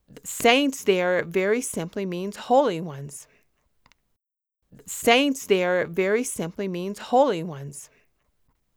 I have been getting subtle honky sound spots throughout my last few tracks.
Its subtle but it sounds like a pig.
IMO the most conspicuous fault is the click on “Saints”.